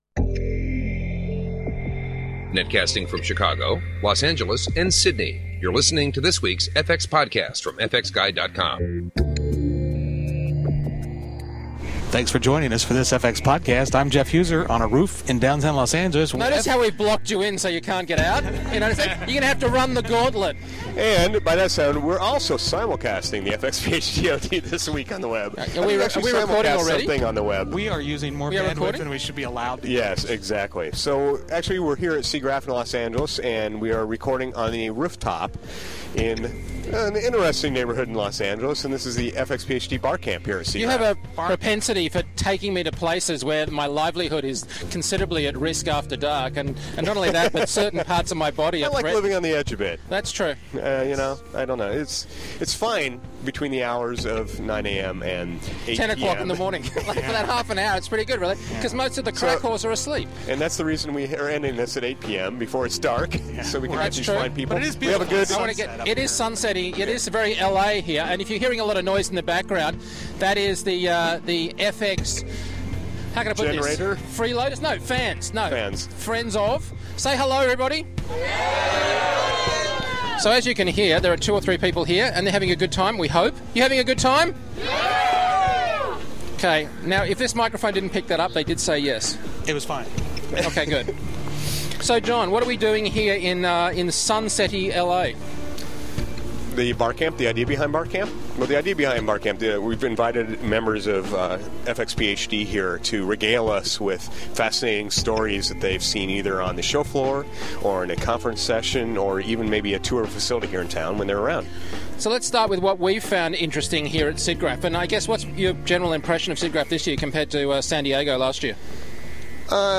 Recorded on a rooftop in downtown Los Angeles at our traditional tradeshow Barcamp. We are joined by fxphd members to discuss what they have seen so far at Siggraph.